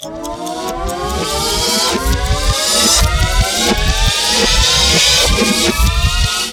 Ripped from the game